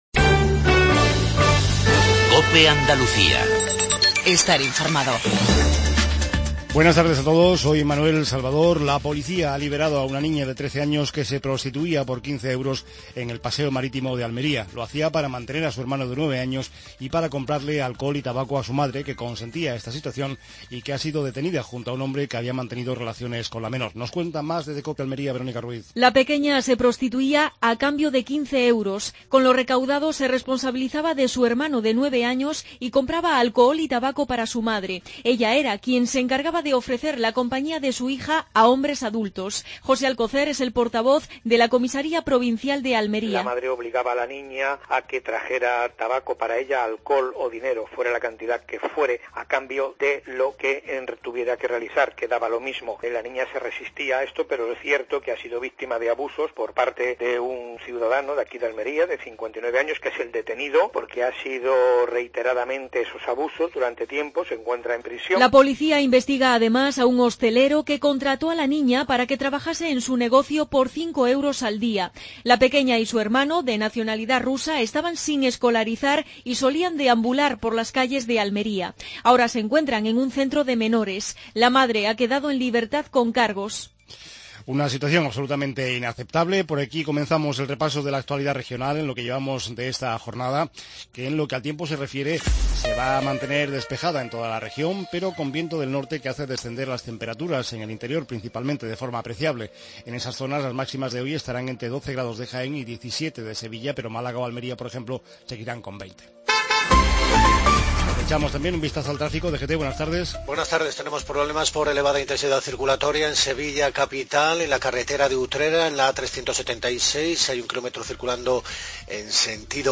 INFORMATIVO REGIONAL MEDIODIA